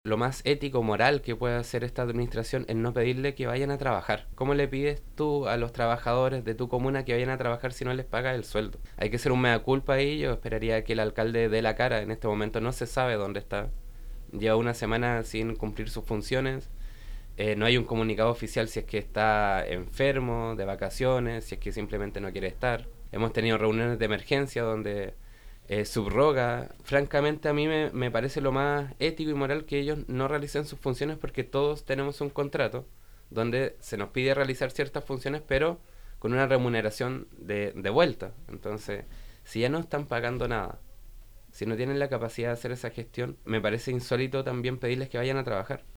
El concejal Cortez, indicó que, desde un aspecto ético, los trabajadores no deberían estar realizando sus funciones, ya que no reciben las remuneraciones correspondientes, por lo que realizó un llamado al jefe comunal para analizar las distintas alternativas.